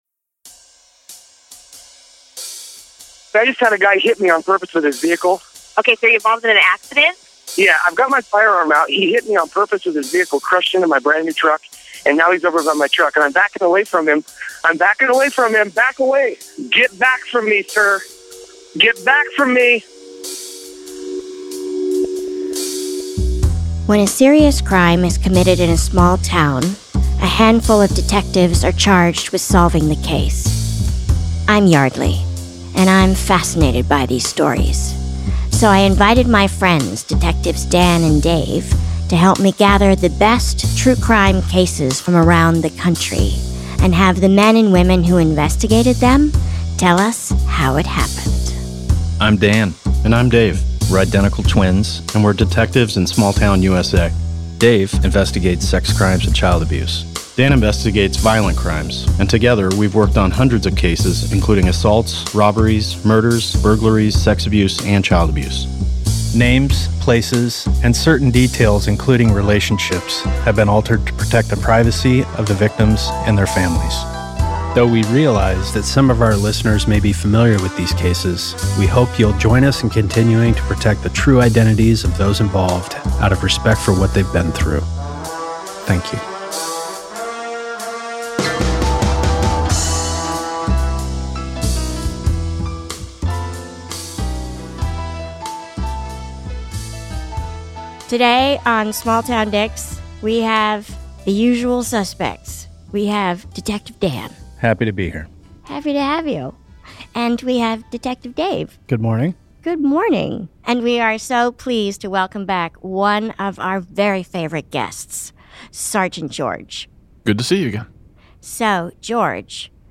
Each episode features the detectives who broke the case in their small town, and includes assets like jailhouse phone calls, suspect interviews and 9-1-1 calls.